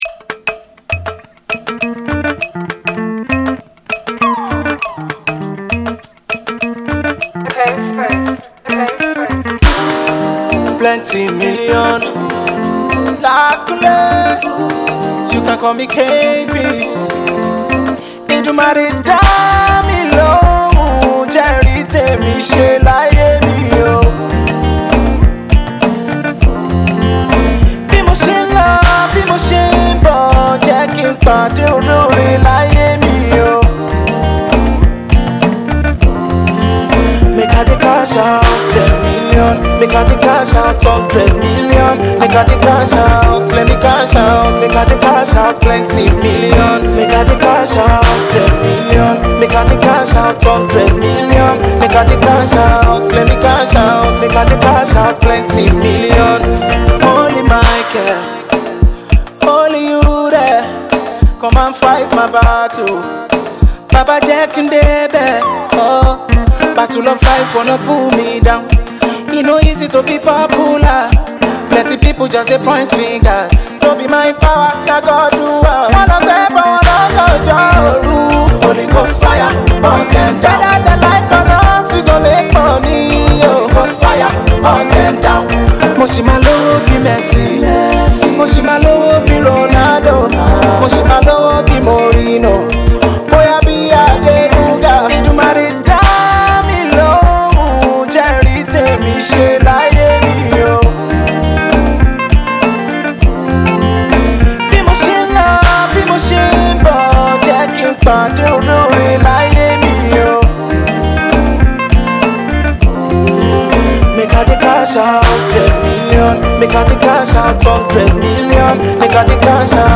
Fuji and blues flavor